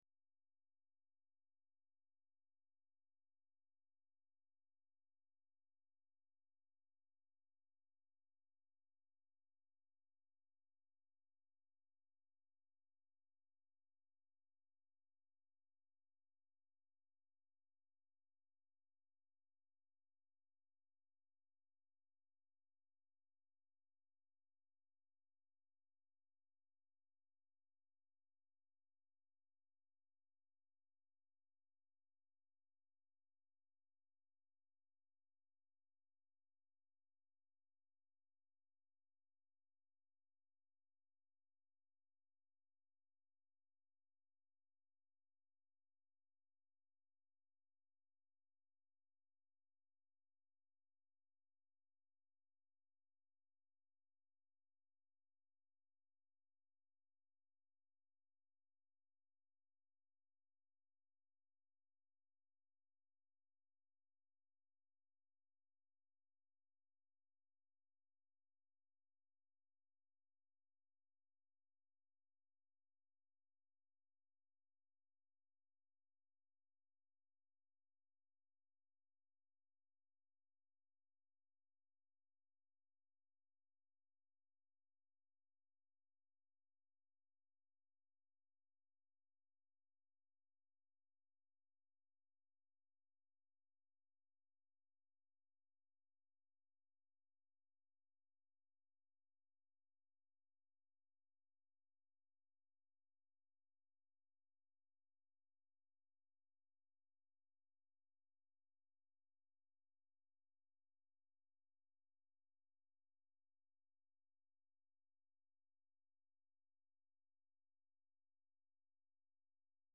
I have been asked whether or not the conversation between Antony Gormley and Rowan Williams last week was recorded.